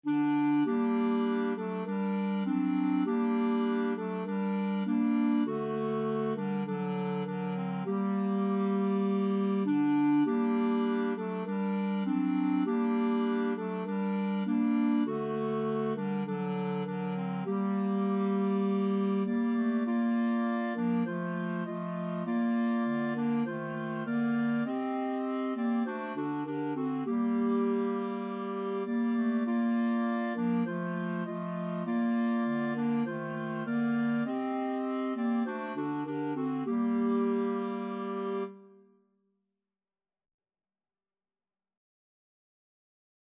Free Sheet music for Clarinet Quartet
Bb major (Sounding Pitch) C major (Clarinet in Bb) (View more Bb major Music for Clarinet Quartet )
4/4 (View more 4/4 Music)
Clarinet Quartet  (View more Intermediate Clarinet Quartet Music)
Christian (View more Christian Clarinet Quartet Music)
Armenian